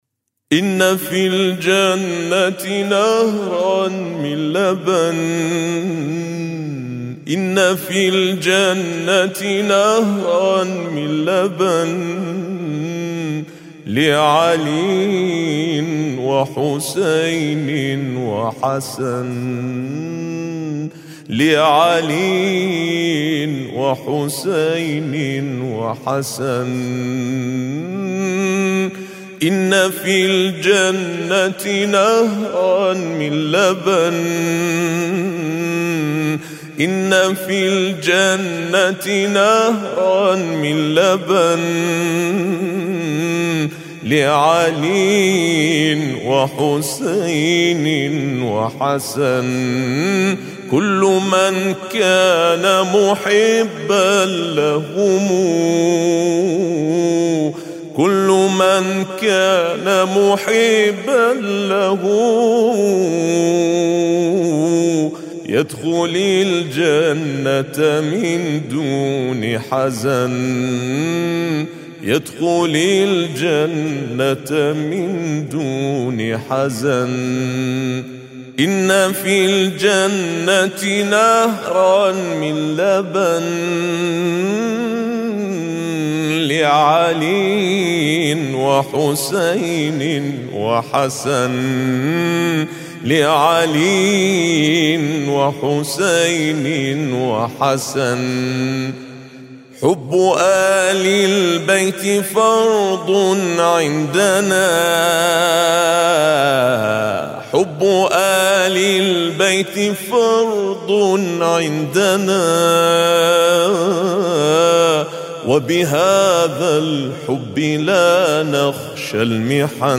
إنّ في الجنة نهراً من لبن - مدائح رائعة